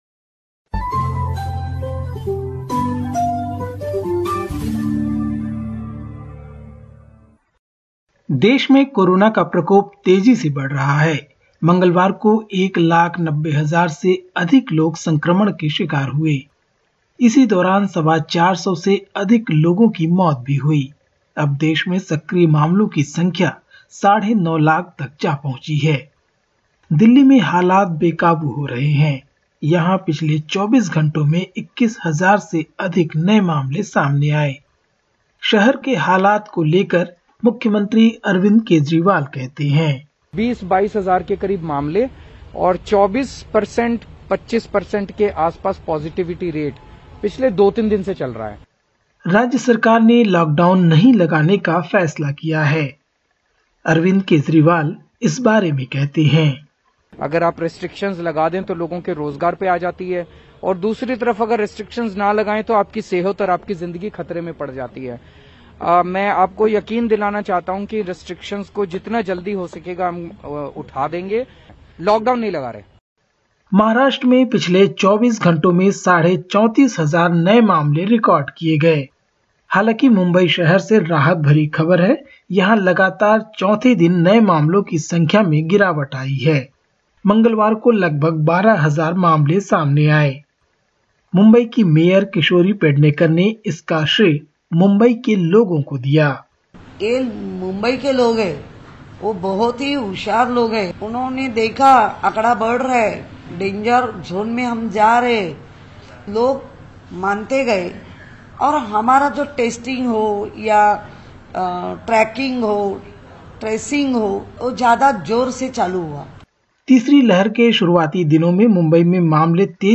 In this latest SBS Hindi report from India: Coronavirus cases continue to rise in India; India's ruling Bharatiya Janata Party (BJP) hits out at the opposition Congress party over Prime Minister Narendra Modi’s security breach; Ban on ritual bath on Hindu festival of Makar Sankranti due to COVID-19 spread and more.